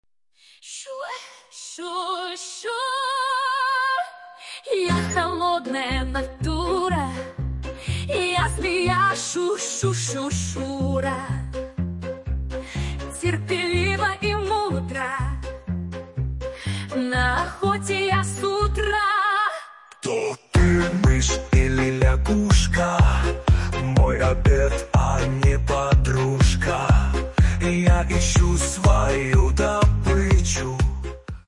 Фрагмент 3-го варианта исполнения (дуэт Змеи и дракона):